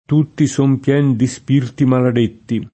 pieno [pL$no] agg. e s. m. — tronc. nella locuz. di pien diritto (o di pieno diritto); talvolta, come agg. m. sing., e più di rado come agg. m. pl., davanti a un complem. (introdotto da di): Un fracasso d’un suon, pien di spavento [un frak#SSo d un SU0n, pL$n di Spav$nto] (Dante); Tutti son pien di spirti maladetti [